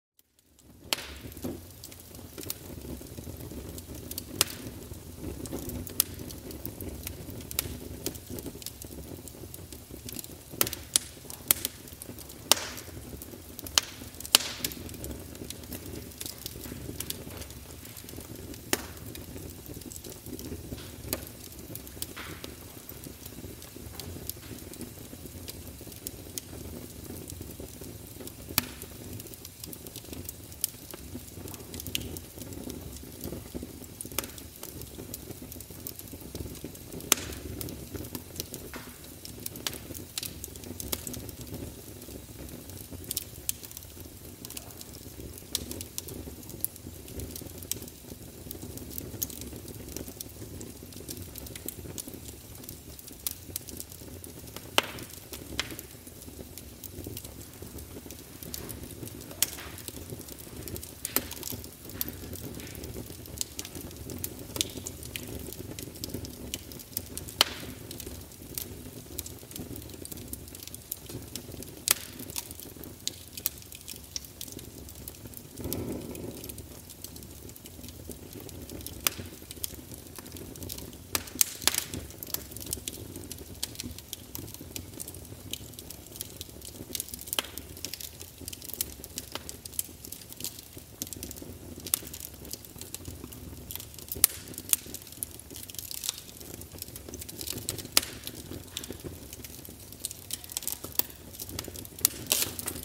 EXEMPLES DE SONS MASQUEURS D'ACOUPHENES
Crépitement du feu :
crepitement-du-feu-cijga.mp3